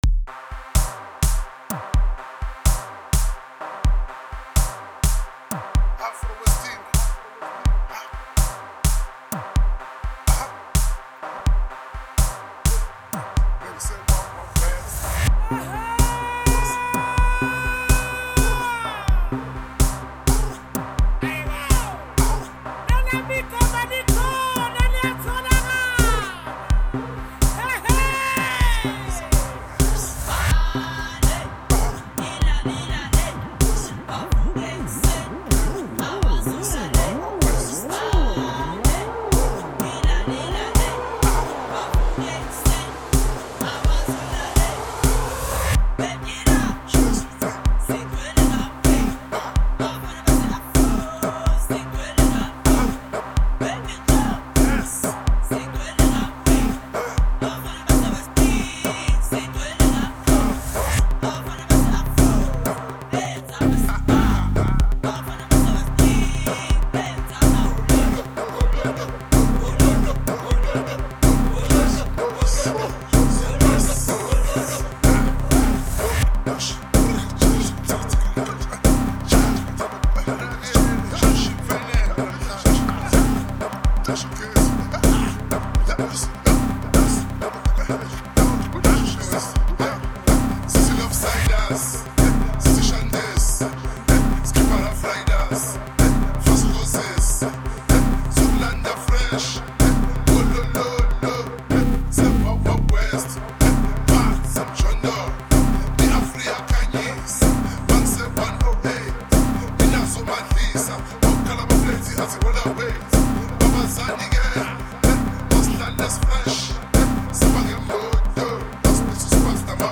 3 months ago Dj Mix